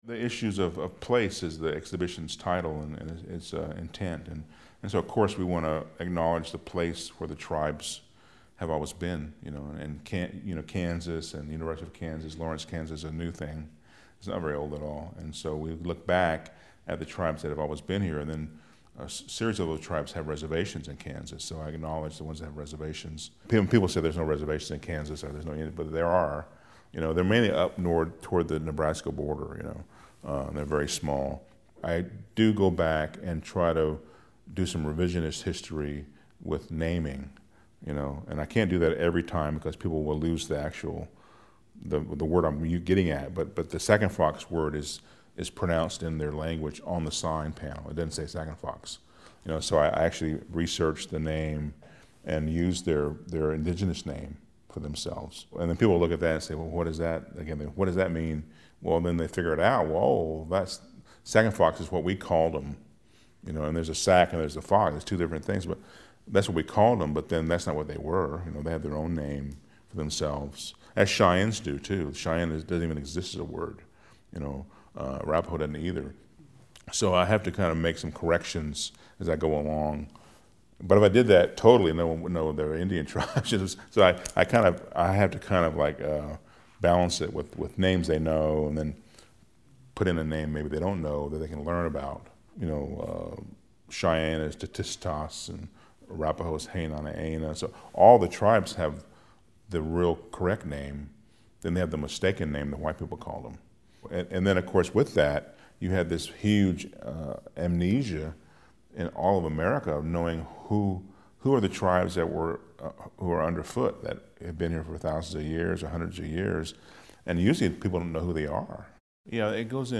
Listen to the artist talk about this work.
Artist Interview